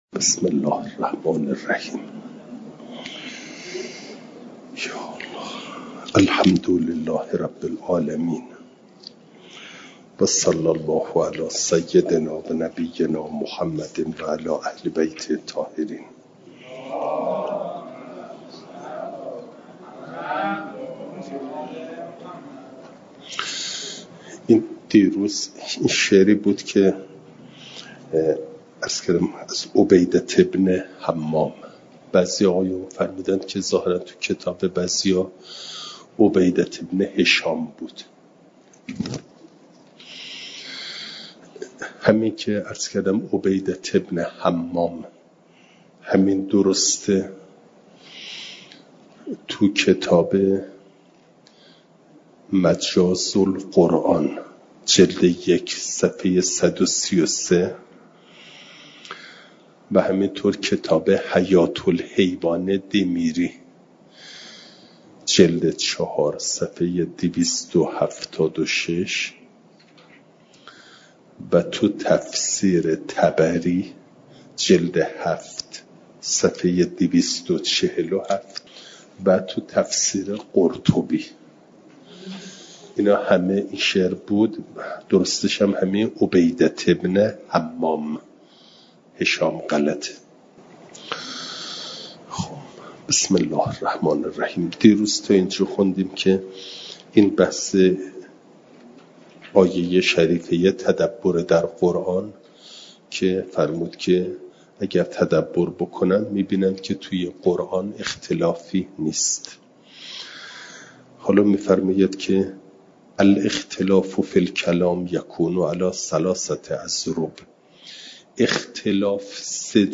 جلسه سیصد و هفتاد و هشتم درس تفسیر مجمع البیان